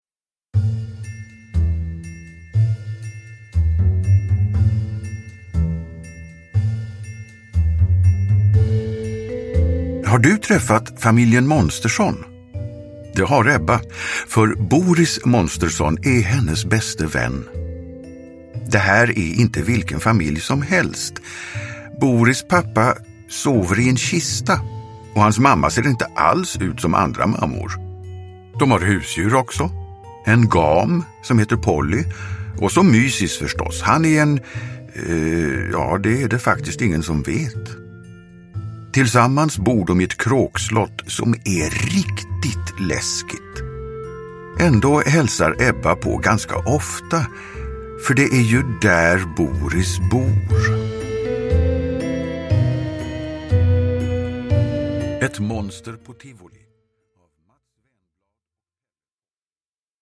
Ett monster på tivoli – Ljudbok – Laddas ner